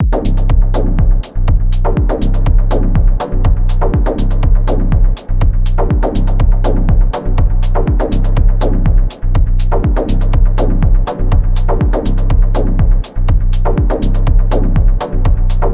I’m definitely having a better time already, I’ve had this less than 24 hours and I’ve already come up with this little sketch/loop this morning which is entirely from scratch except for a modified kick drum preset which also serves as the snare/clap with some P-locking. I hope this is a good omen that I can make some minimalistic electronic music with one box and no computer (this was recorded into Bitwig as stereo, no extra processing apart from a peak limiter).
I know it goes without saying but this thing is a dub techno dream, so easy to get great dubby chords and drown in delay.